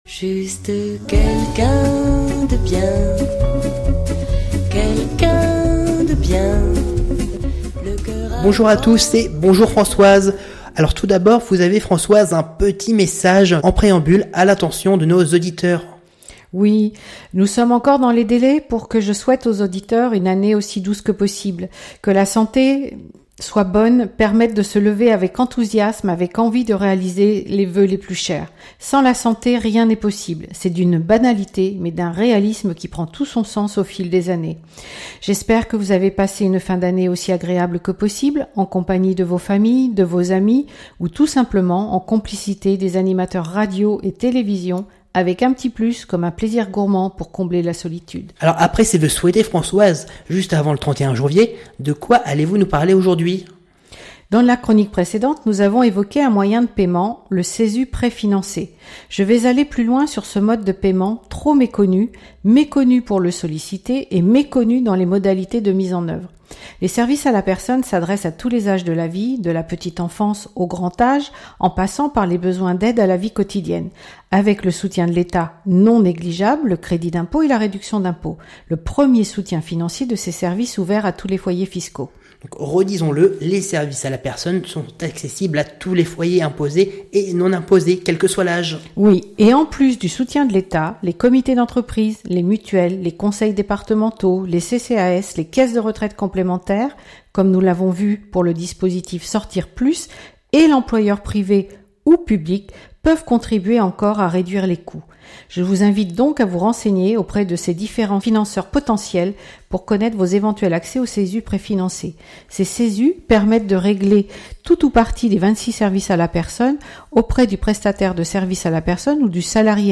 Chronique radio – Services À la Personne – CESU Préfinancé